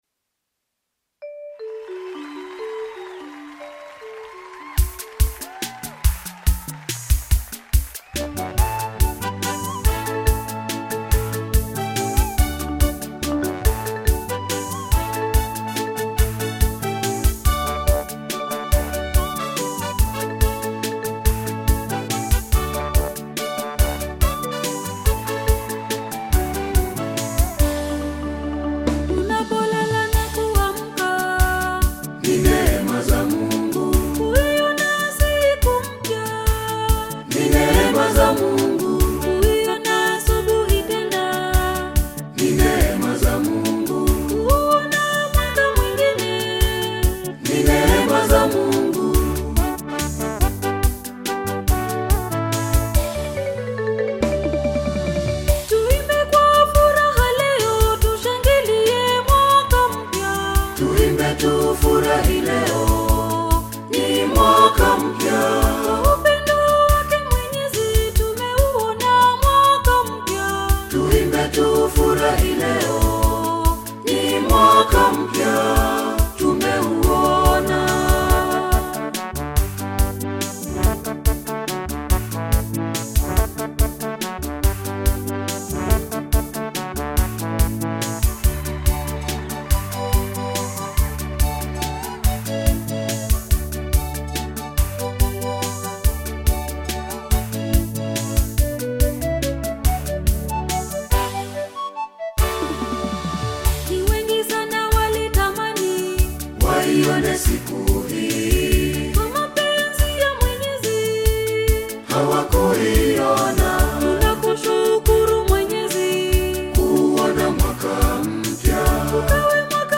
Roman Catholic gospel
gospel song
Gospel song